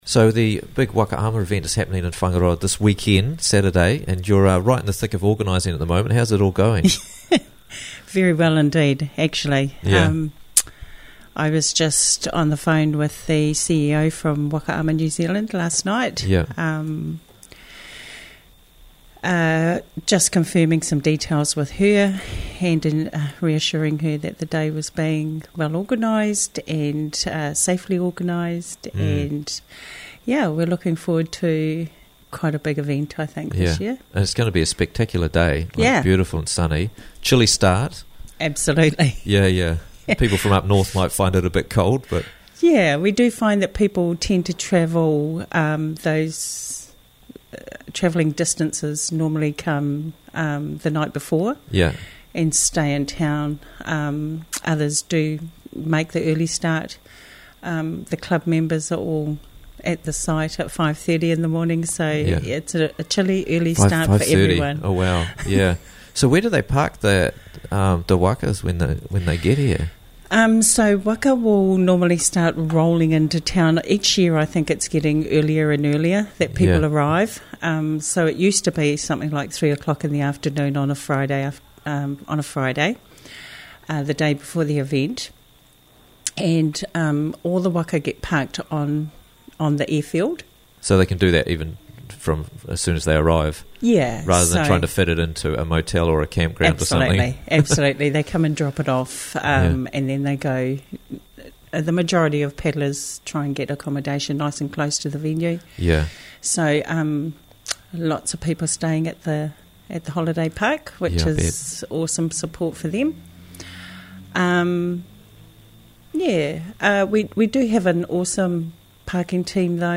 Waka Ama This Saturday - Interviews from the Raglan Morning Show